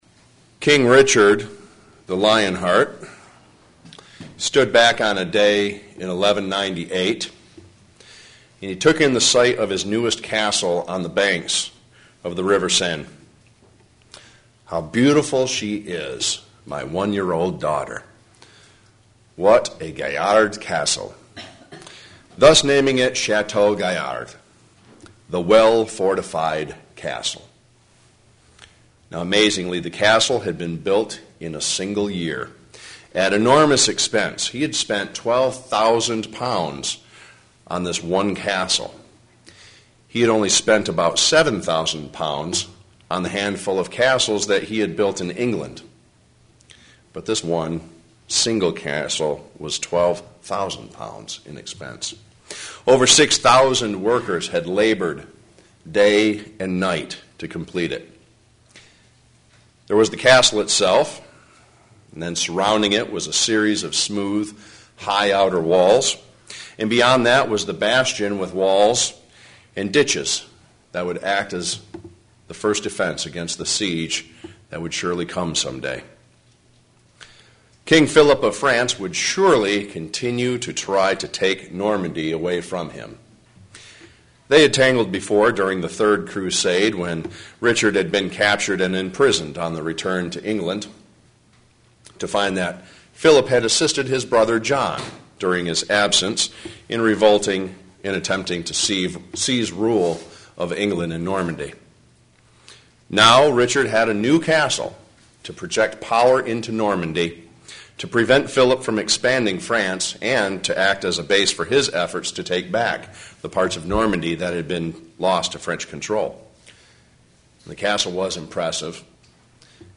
What can we do to keep from getting devoured? sermon Studying the bible?